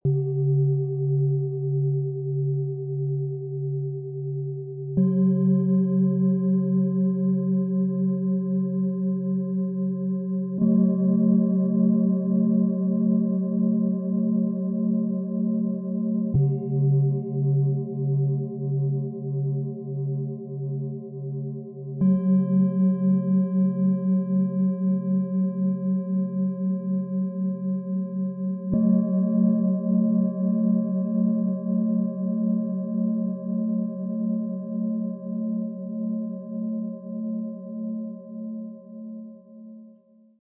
Finde zurück in deinen Rhythmus - Set aus 3 Biorhythmus - Klangschalen - für Körper, Geist und Seele, Ø 14,4 -18,3 cm, 1,76 kg
Dieses handgefertigte Klangschalen-Set mit drei abgestimmten Planetentönen ist dein liebevoller Begleiter zurück in die innere Balance.
Die Tiefe Schwingung stärkt deine Lebenskraft und zentriert dich.
Ein sanfter, heller Klang, der auf der seelischen Ebene berührt.
• Klang: Präzise abgestimmte Frequenzen erzeugen ein harmonisches Klangbild.
Im Sound-Player - Jetzt reinhören kannst du den Original-Klang genau dieser Schalen anhören – authentisch und unverfälscht.
Tiefster Ton: Biorhythmus Körper & Geist
Mittlerer Ton: Biorhythmus Geist & Körper
Höchster Ton: Biorhythmus Seele & Wasserstoffgamma